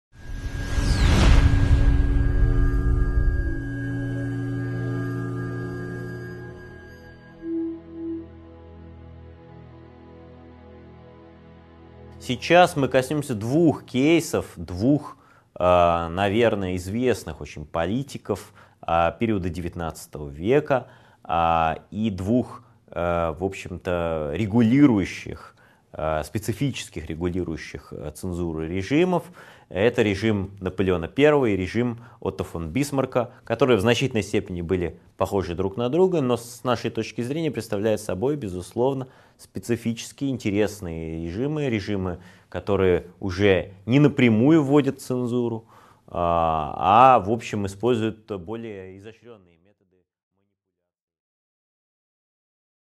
Аудиокнига 3.8 Цензура при Наполеоне | Библиотека аудиокниг